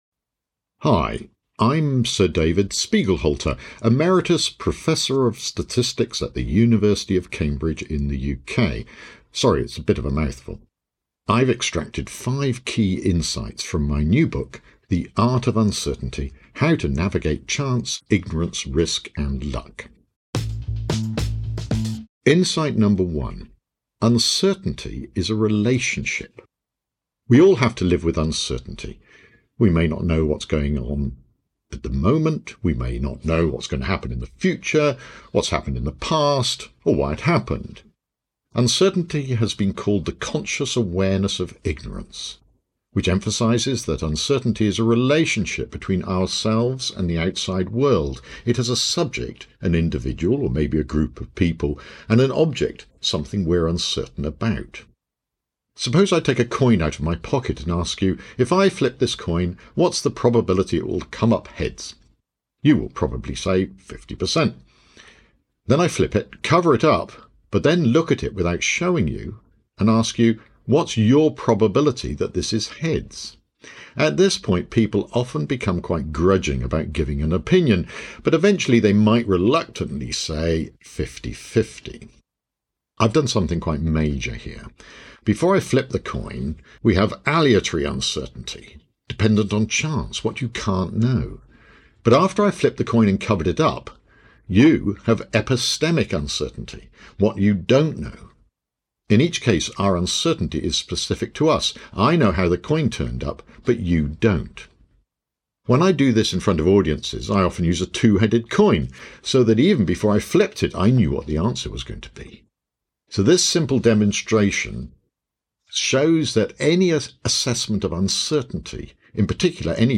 Listen to the audio version—read by David himself—in the Next Big Idea App.